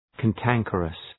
Προφορά
{kæn’tæŋkərəs}